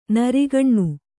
♪ narigaṇṇu